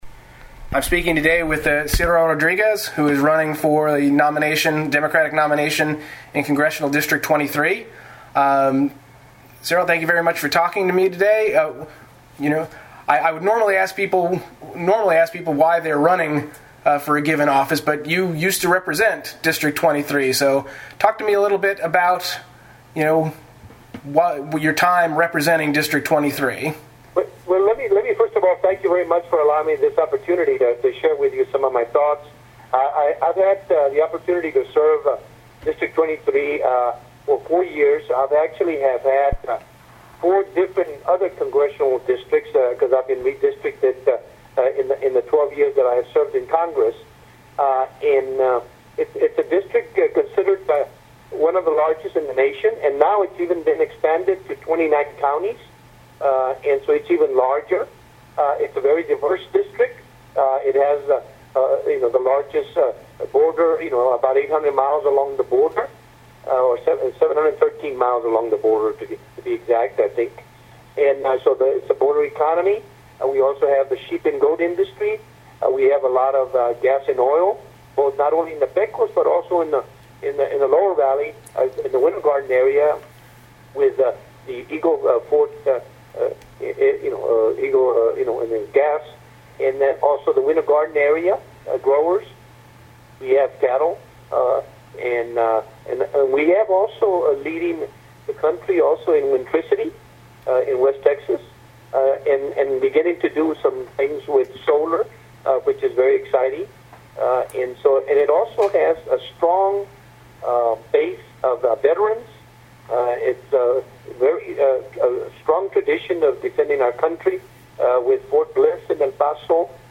Interview with Ciro Rodriguez | Off the Kuff